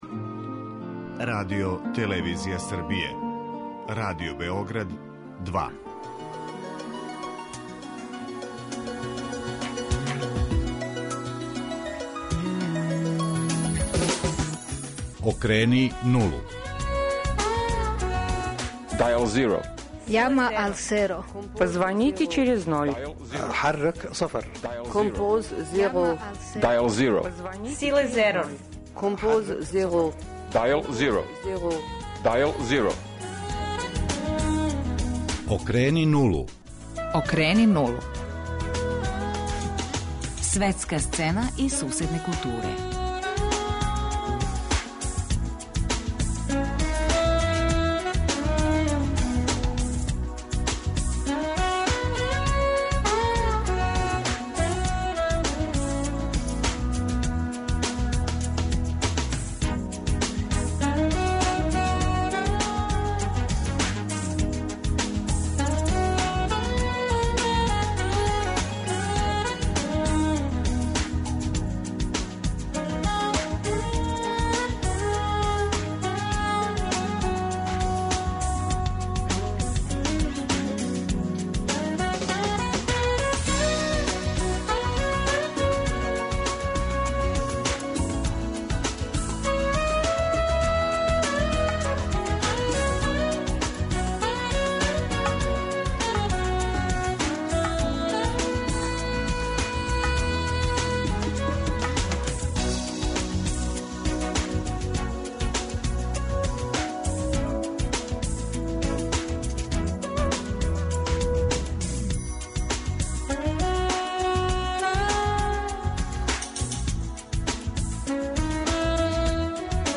То ће нам омогућити извештаји дописника Радио Београда 2.